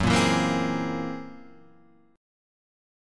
Gb9b5 chord